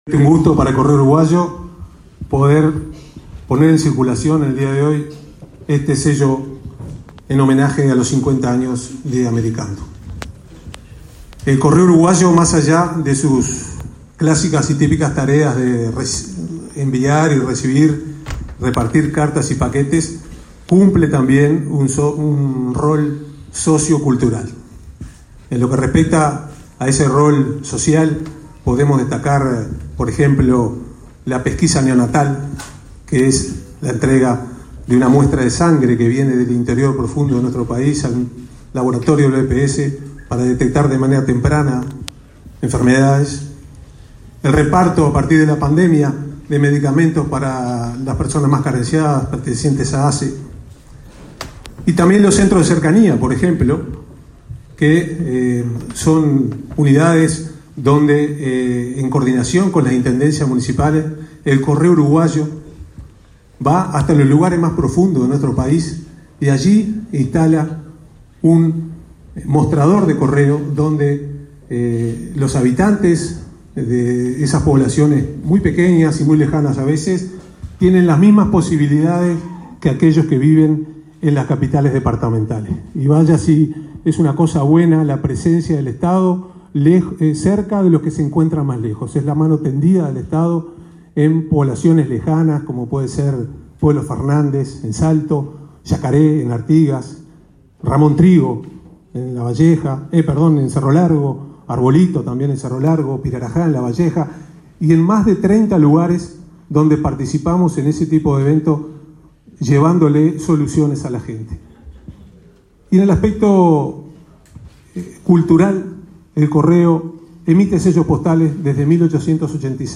Palabras del presidente del Correo Uruguayo, Rafael Navarrine
Palabras del presidente del Correo Uruguayo, Rafael Navarrine 29/11/2022 Compartir Facebook X Copiar enlace WhatsApp LinkedIn Este 28 de noviembre se realizó el acto por el 50.° aniversario del programa Americando, con la presencia del presidente de la República, Luis Lacalle Pou.